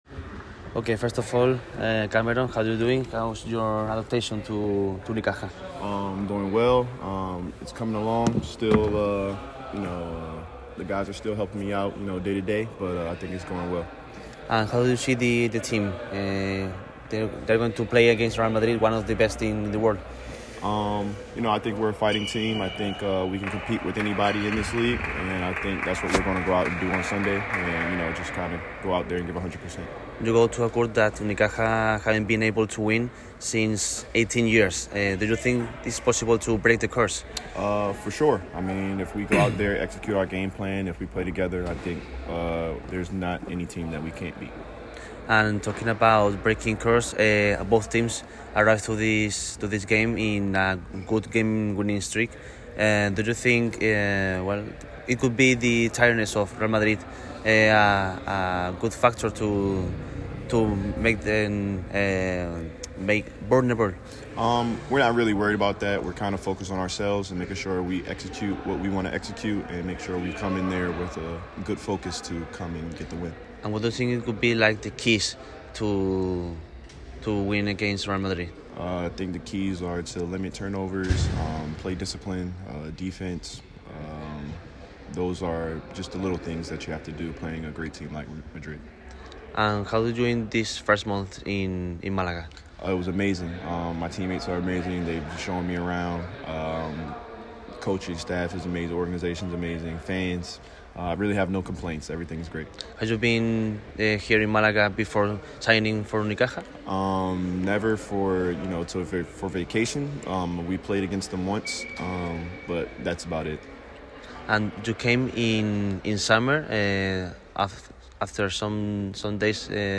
El escolta americano habló en exclusiva para el micrófono rojo.